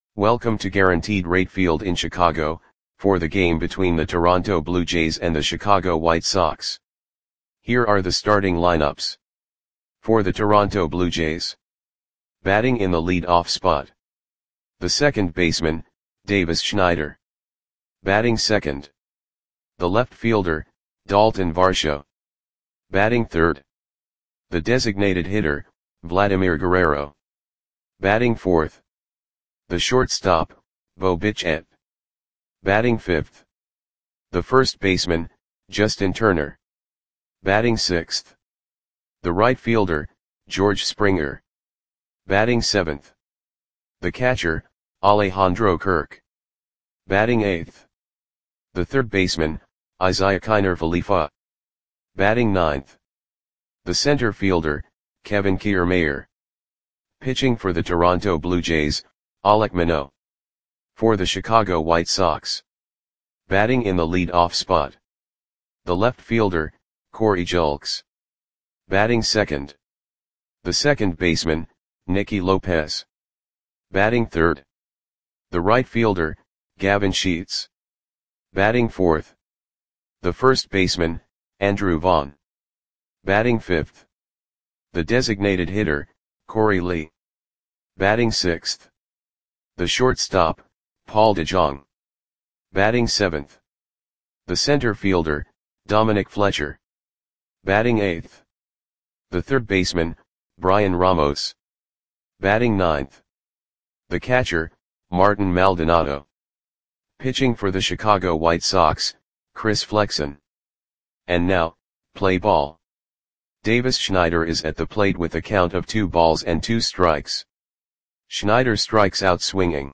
Audio Play-by-Play for Chicago White Sox on May 29, 2024